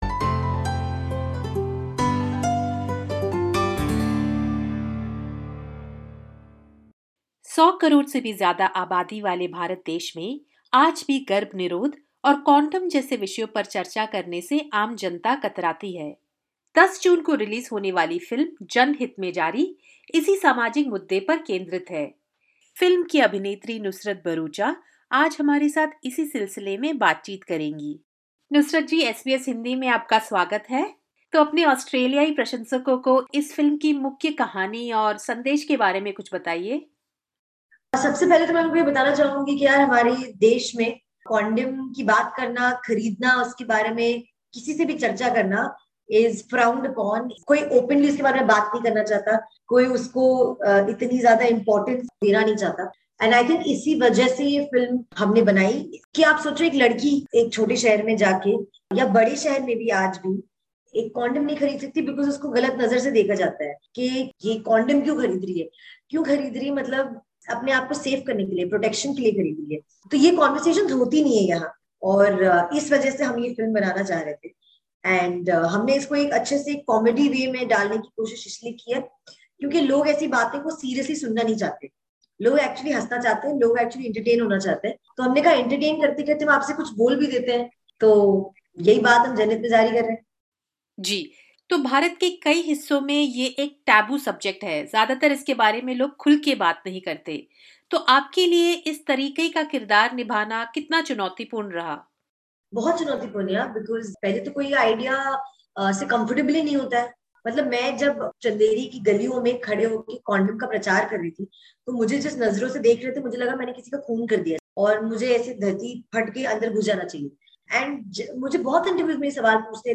She speaks exclusively with SBS Hindi about the challenges of playing the character in the movie.